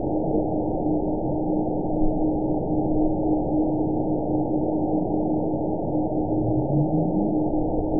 event 911976 date 03/14/22 time 00:02:10 GMT (3 years, 2 months ago) score 9.61 location TSS-AB02 detected by nrw target species NRW annotations +NRW Spectrogram: Frequency (kHz) vs. Time (s) audio not available .wav